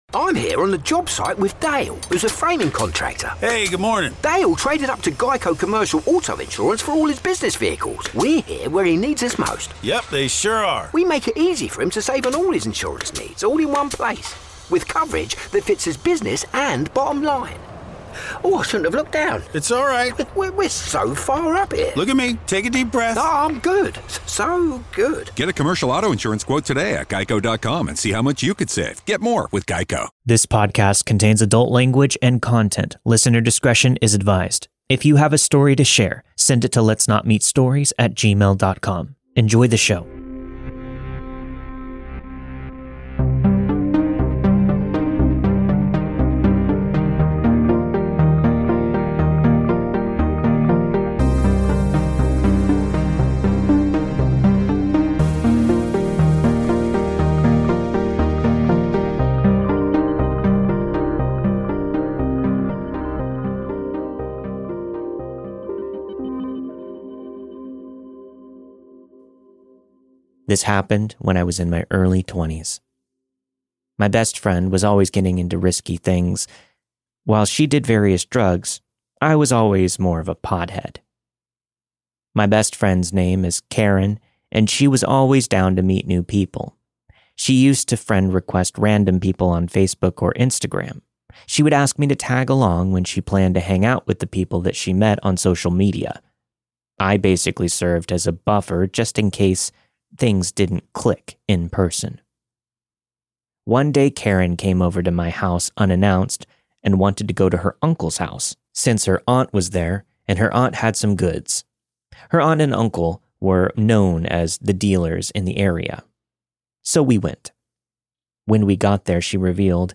All of the stories you've heard this week were narrated and produced with the permission of their respective authors.